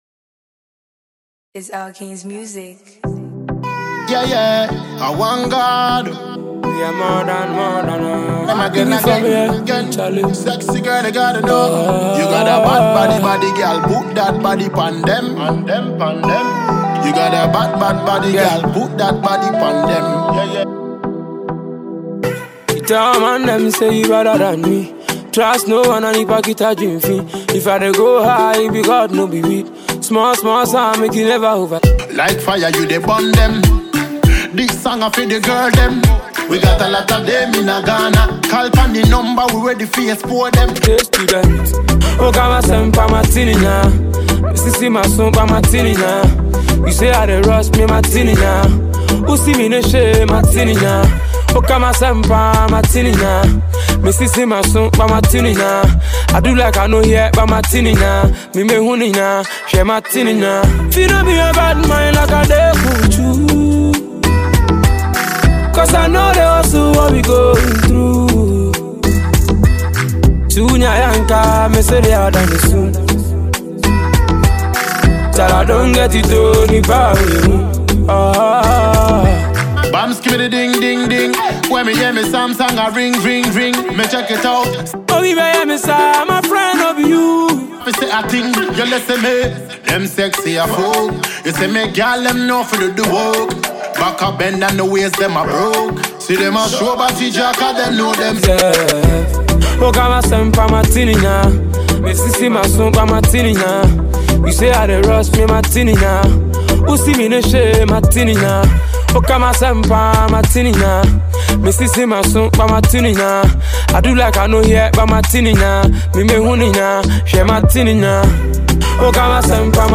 Ghana MusicMusic